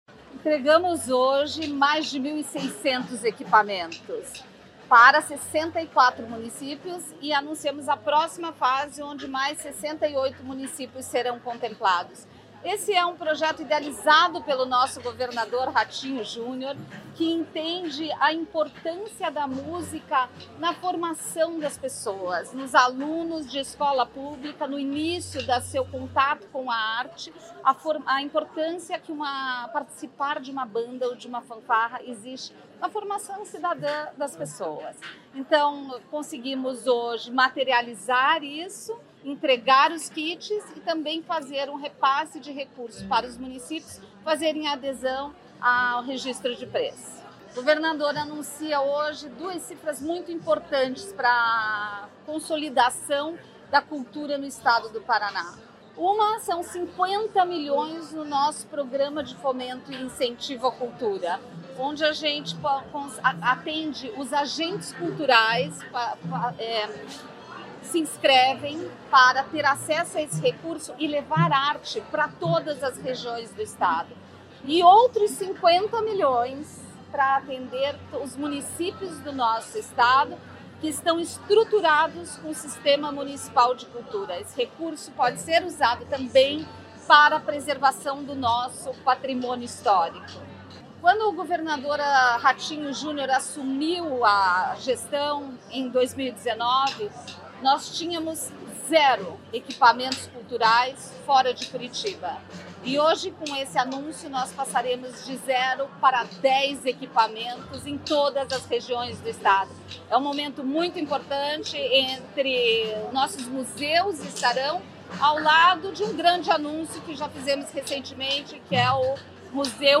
Sonora da secretária da Cultura, Luciana Casagrande Pereira, sobre entrega de instrumentos musicais e a liberação de R$ 100 milhões para a cultura